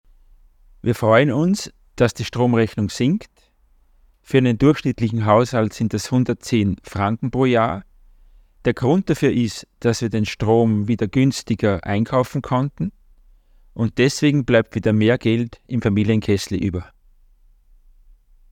O-Ton-Statement zum EKZ-Stromtarif 2025